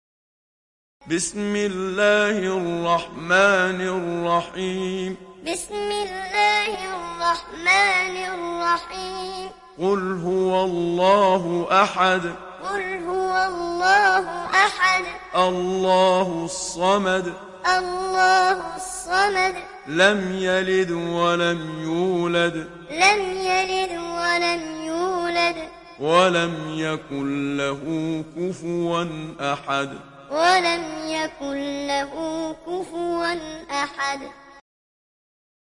دانلود سوره الإخلاص mp3 محمد صديق المنشاوي معلم (روایت حفص)
دانلود سوره الإخلاص محمد صديق المنشاوي معلم